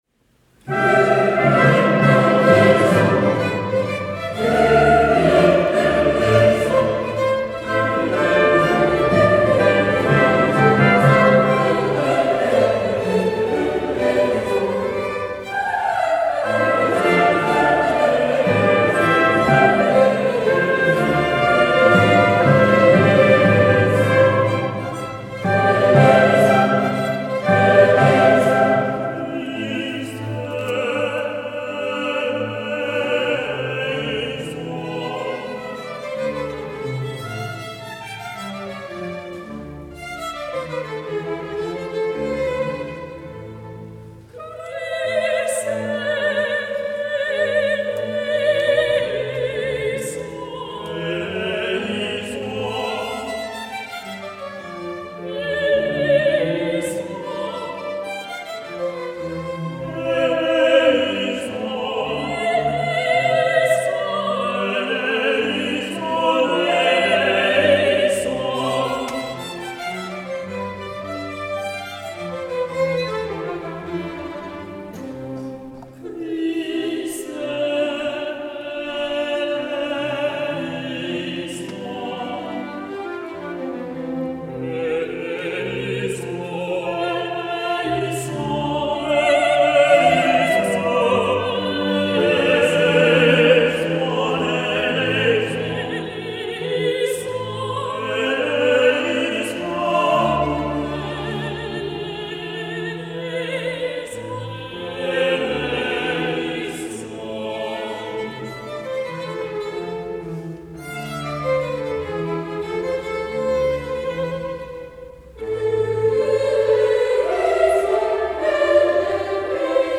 Festkonzert zum 300.
Marienkirche Gleisdorf
Sopran
Tenor
Chorgemeinschaft des Dekanates Gleisdorf Instrumentalensemble Gleisdorf
Satz - Andante - Te Deum Von diesem Festkonzert gibt es einen Livemitschnitt auf CD Hörprobe - Kyrie aus der Messe (3.55 MB) Image